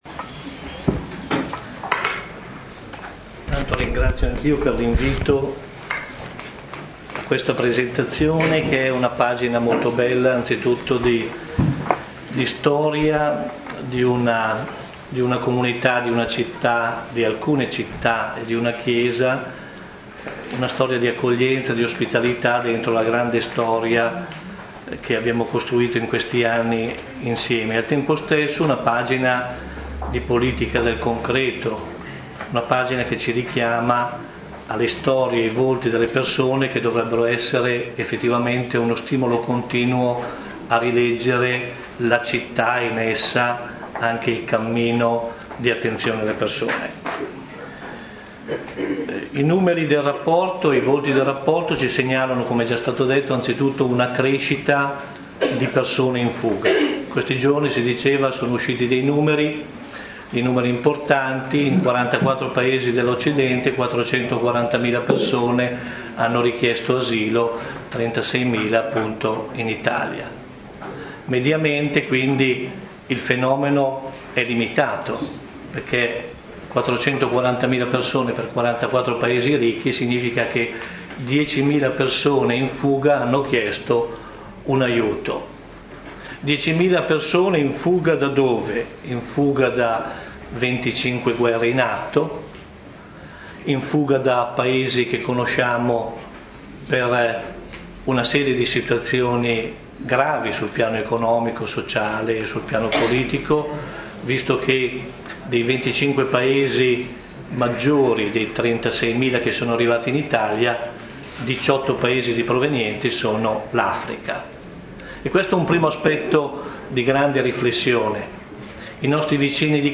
Intervento di Mons. Giancarlo Perego, Direttore generale Fondazione Migrantes.